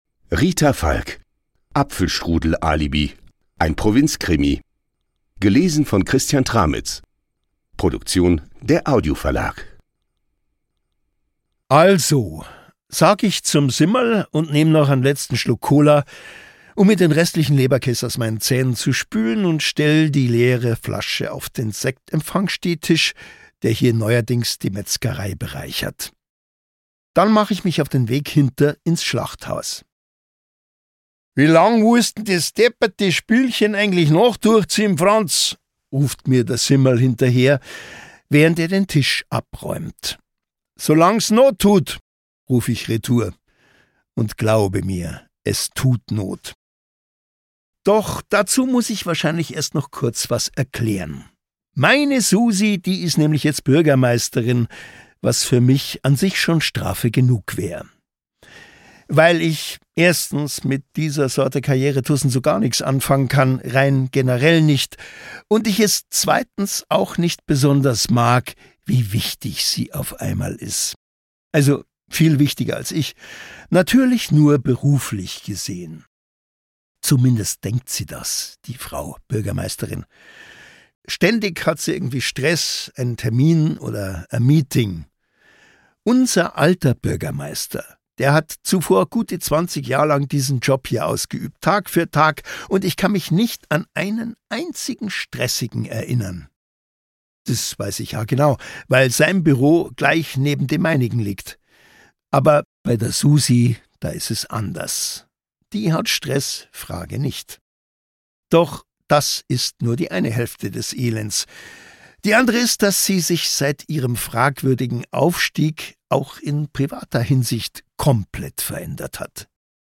Christian Tramitz (Sprecher)
Auch der 13. Fall der Provinzkrimi-Reihe um den Eberhofer ist eine urkomische Gaudi, genial gesprochen von Christian Tramitz.
Ungekürzte Lesung mit Christian Tramitz